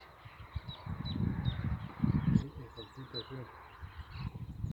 Freckle-breasted Thornbird (Phacellodomus striaticollis)
Detailed location: Laguna de Gómez
Condition: Wild
Certainty: Observed, Recorded vocal
Espinero-pecho-manchado_2.mp3